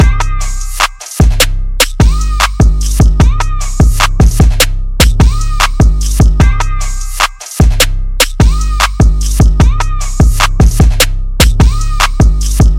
爆炸 " 爆炸04
描述：Audacity的爆炸声具有白噪声和其他类型的噪音。
标签： 爆炸 爆炸 炸弹
声道立体声